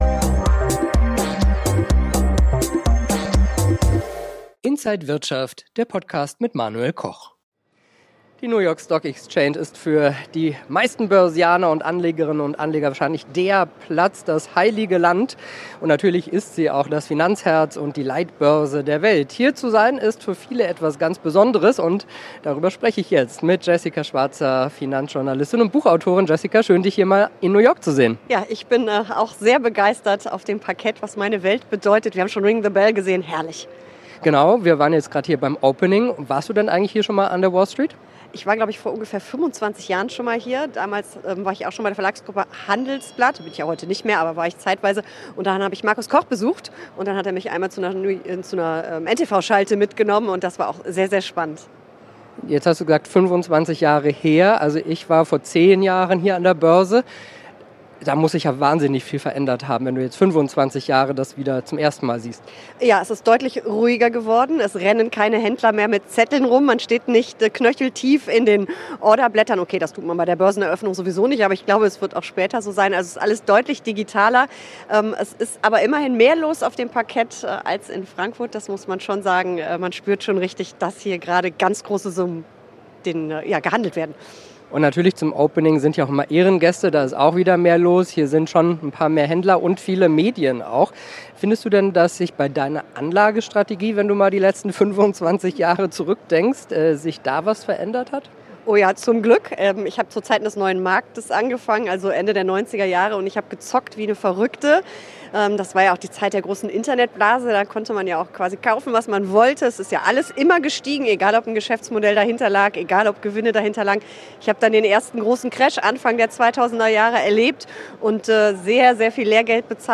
Interview
an der New York Stock Exchange